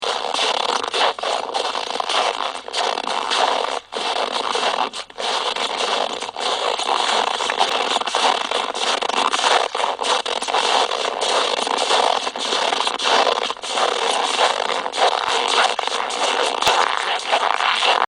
Today on my way back from the Svalbardbutikken (the Co-op store where we buy everything we need), I thought I would capture a few sounds during my 3 km walk home.
sound of my footsteps...
footsteps.mp3